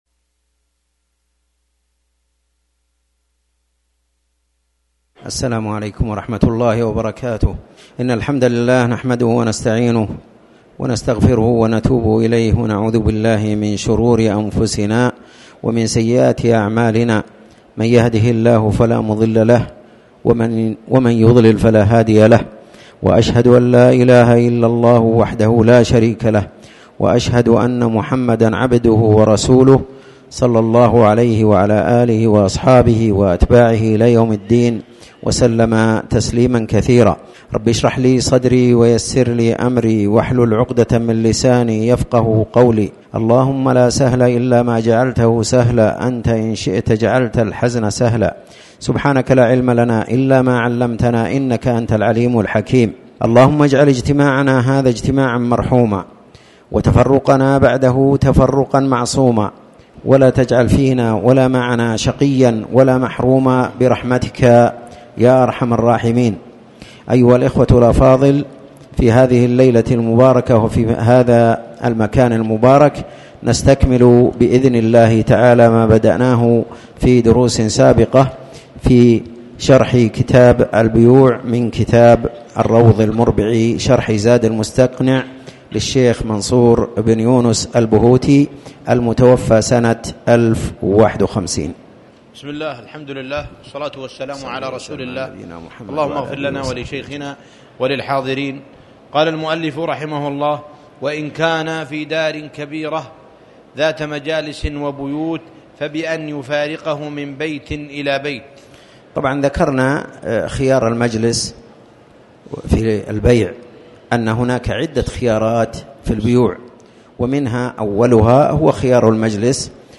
تاريخ النشر ١ شعبان ١٤٣٩ هـ المكان: المسجد الحرام الشيخ